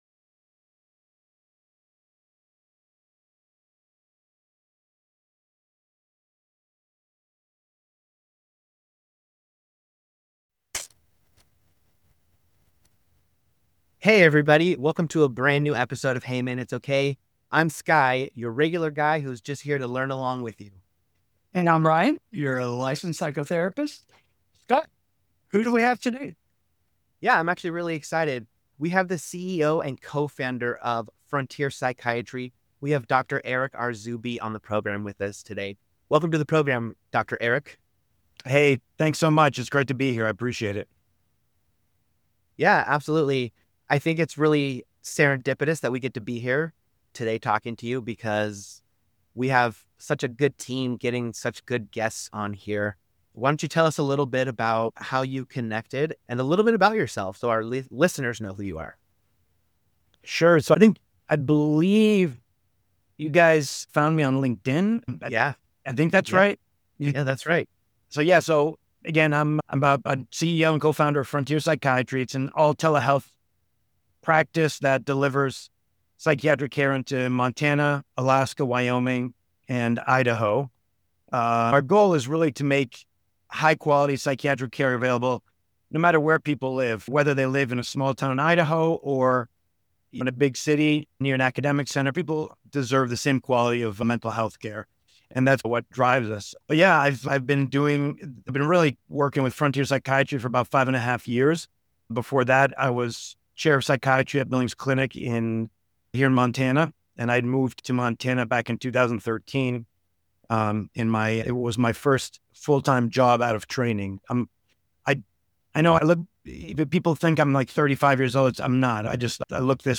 for a conversation that goes far beyond credentials and titles.